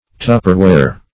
Tupperware \Tupperware\, Tupper ware \Tup"per ware\, n. [Trade